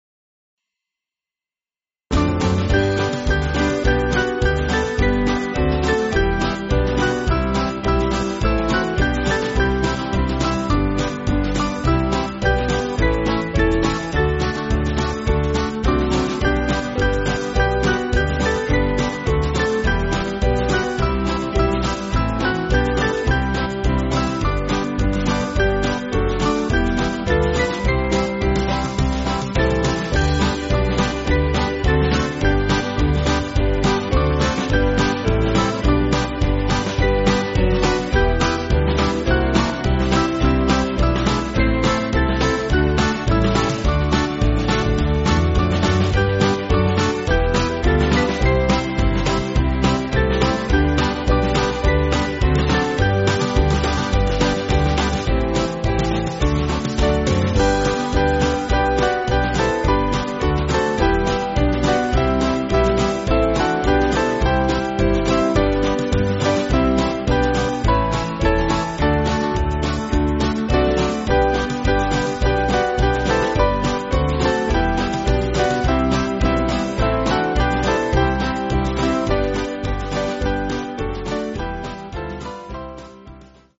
Small Band
(CM)   5/G